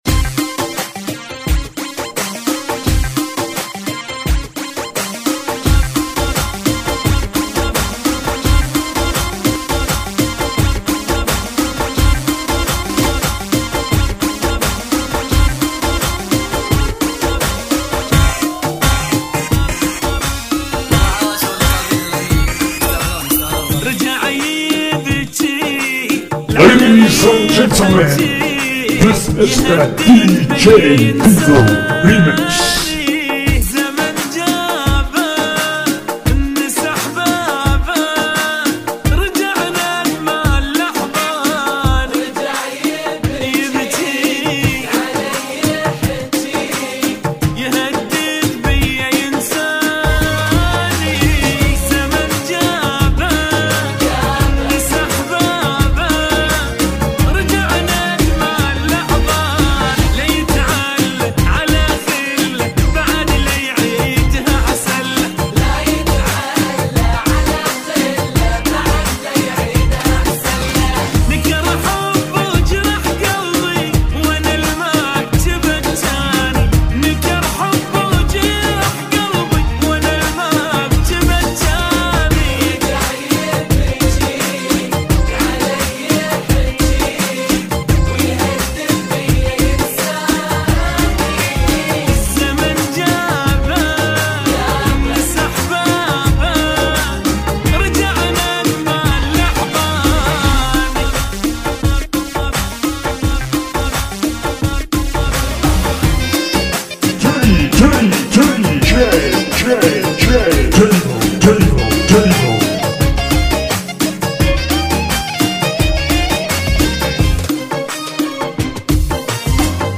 ريمكسي